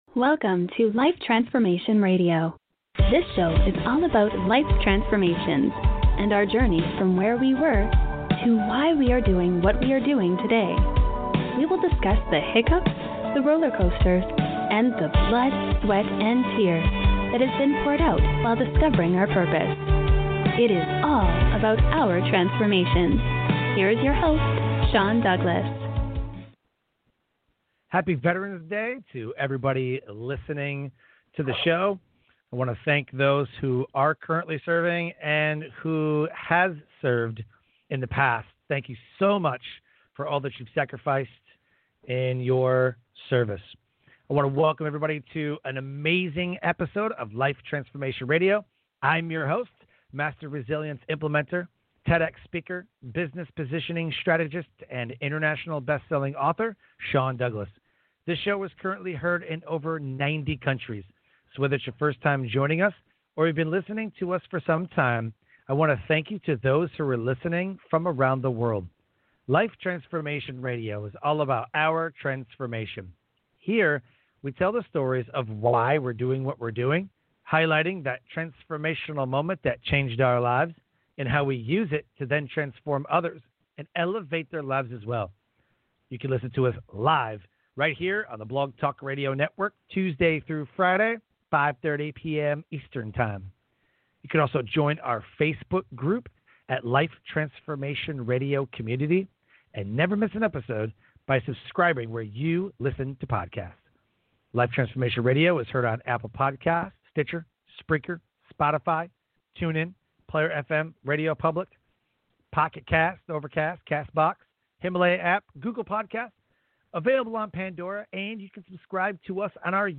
interviewed on Life Transformation Radio